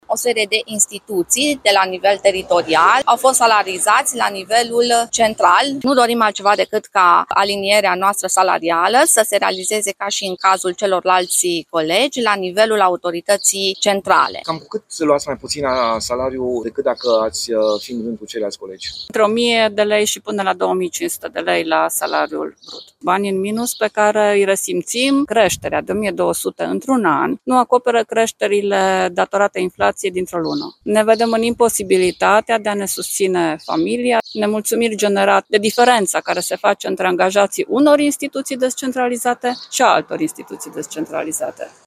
02-voxuri-proteste-Arad.mp3